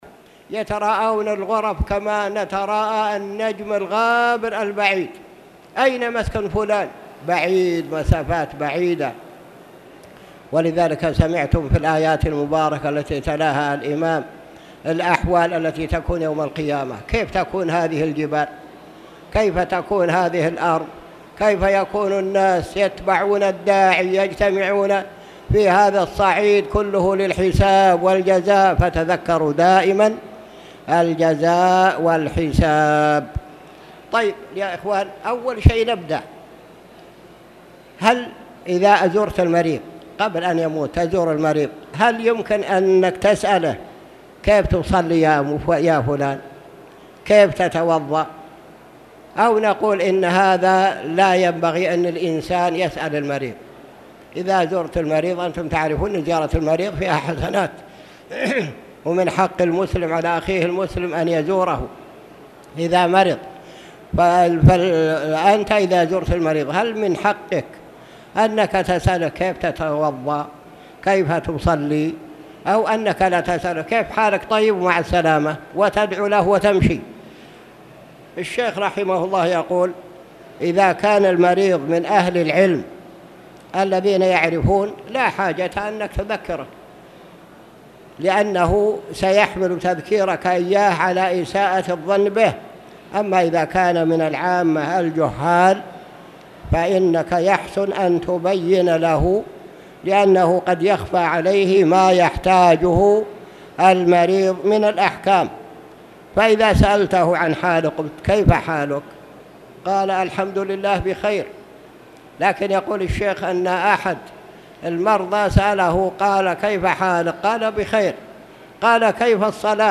تاريخ النشر ٢٠ شوال ١٤٣٧ هـ المكان: المسجد الحرام الشيخ